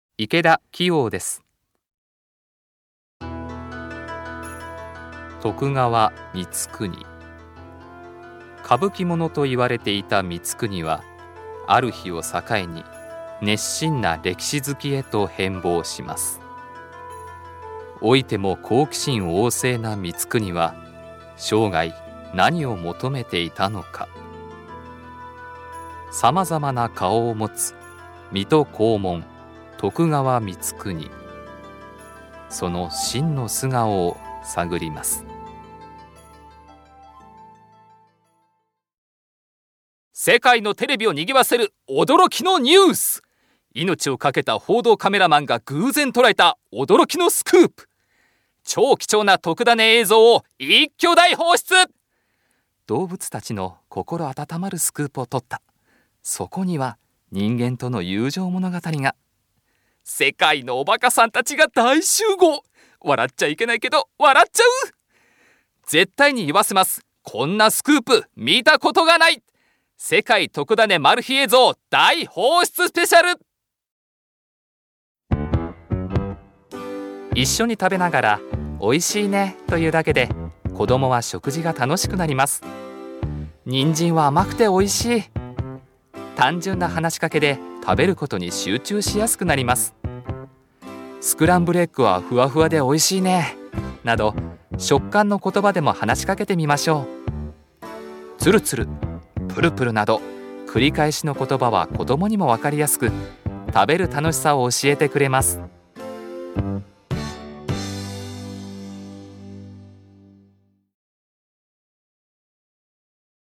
• ナレーター
VOICE SAMPLE